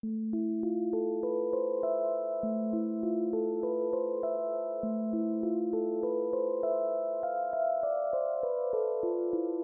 Tag: 100 bpm Chill Out Loops Organ Loops 1.62 MB wav Key : Unknown